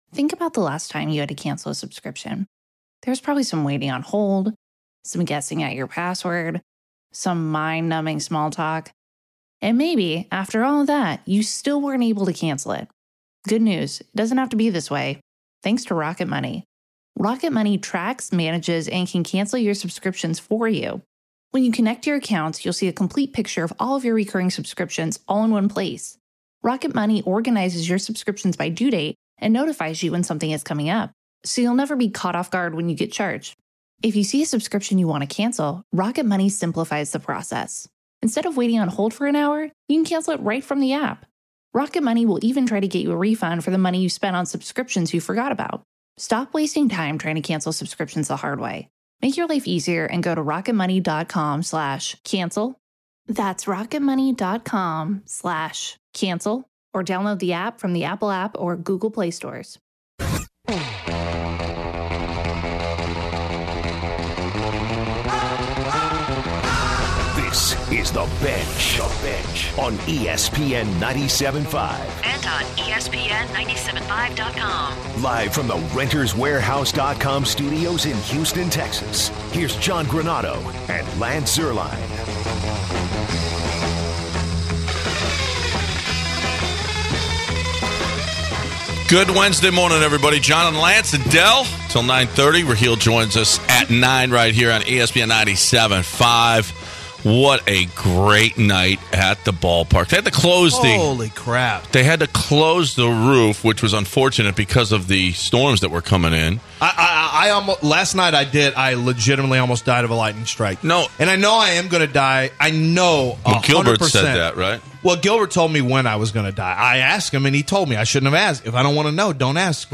In the first hour of The Bench, the guys had a heated argument about Tiger Woods. After a brief hiatus the topic turned the Astros and Justin Verlander.